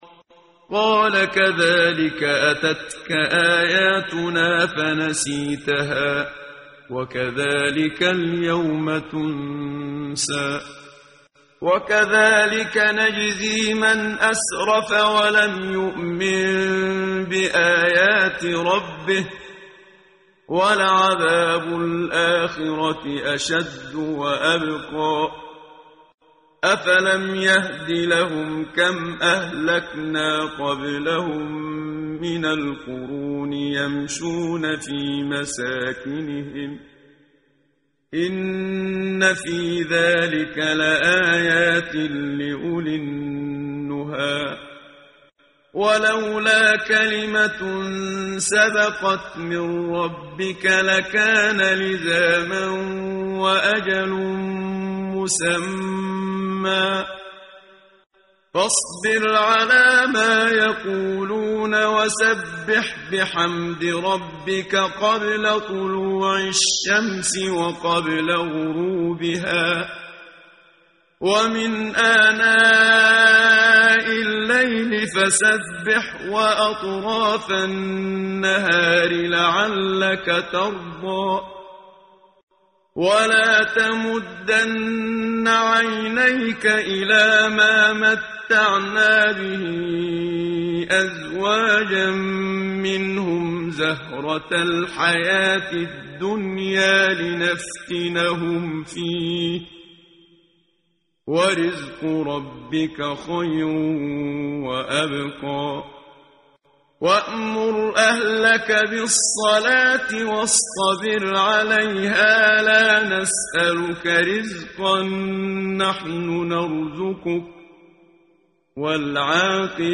قرائت قرآن کریم ، صفحه 321 ، سوره مبارکه طه آیه 126 تا 135 با صدای استاد صدیق منشاوی.